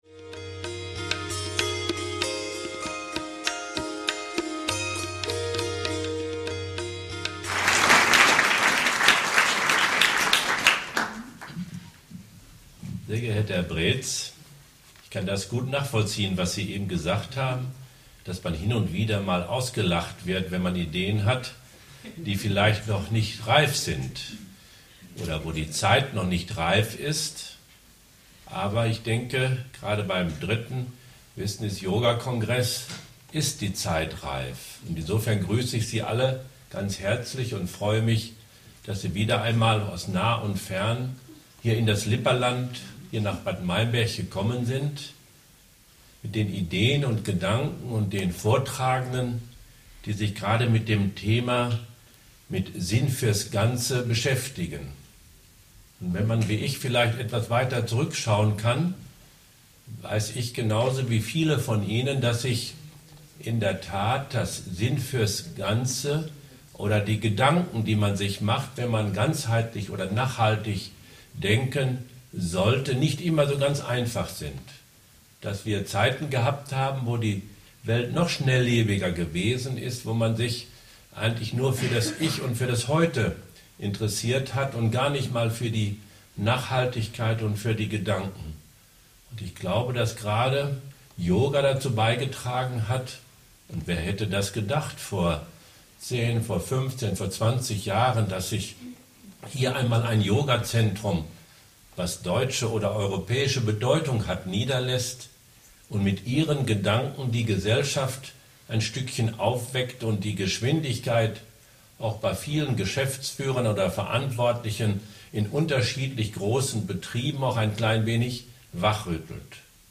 Eröffnungsrede Teil 2 mit Landrat Friedel Heuwinkel - Business Yoga Kongress 2014
2_BusinessKongress_2014_Eroeffnungsrede_Landrat_FriedelHeuwinkel.mp3